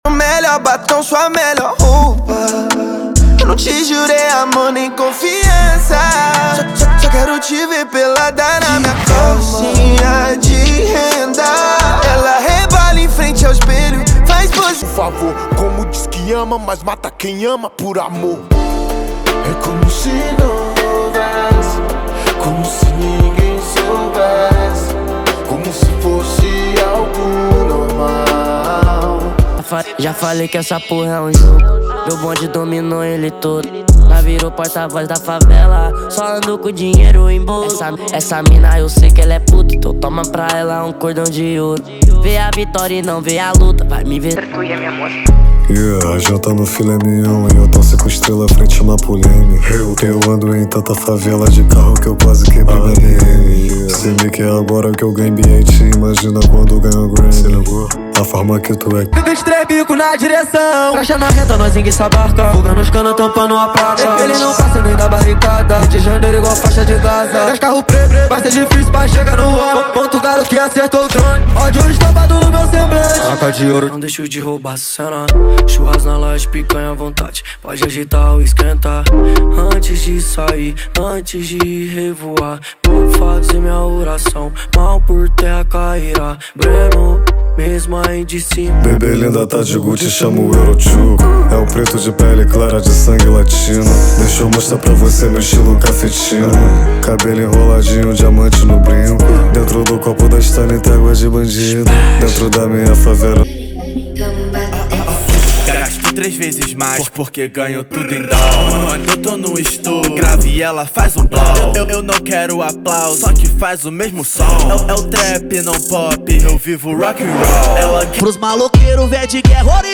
Os Melhores Rap/Trap Nacionais do momento estão aqui!!!
• Rap, Trap Nacional e Funk Ostentação = 50 Músicas
• Sem Vinhetas